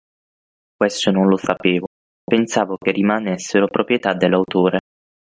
pro‧prie‧tà
/pro.prjeˈta/